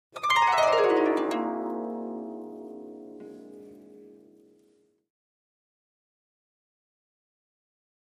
Harp, Medium Strings, 7th Chord, Short Descending Gliss, Type 3